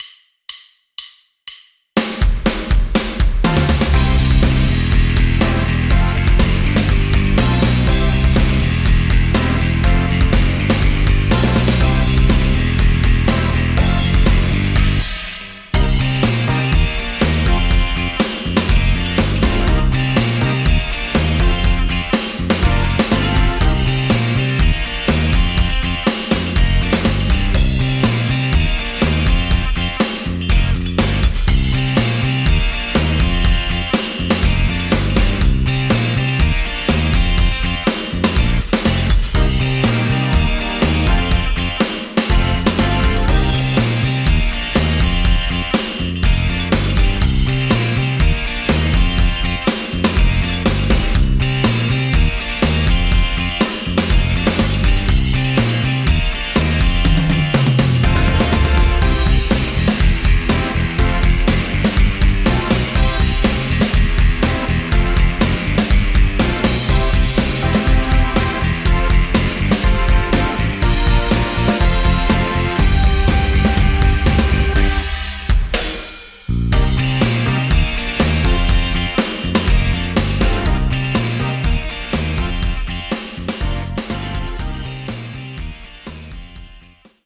Instrumental, Karaoke